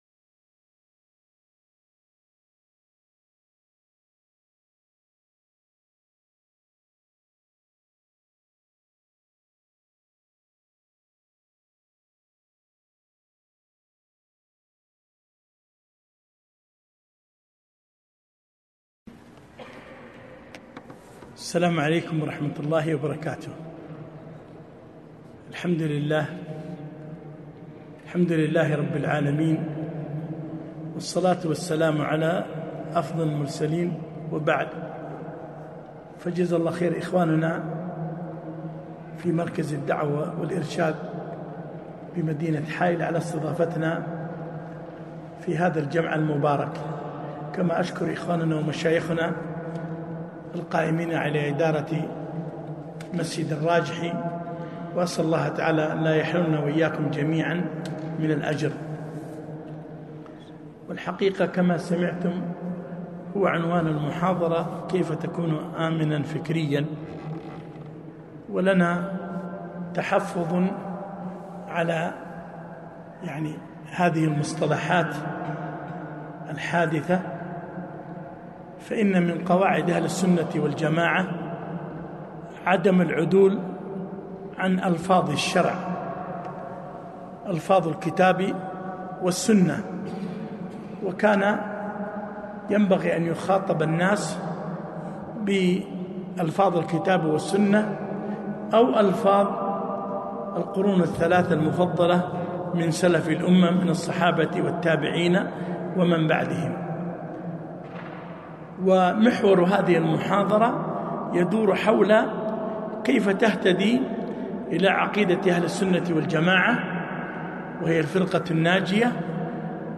محاضرة - حتى تكون آمنا فكرياً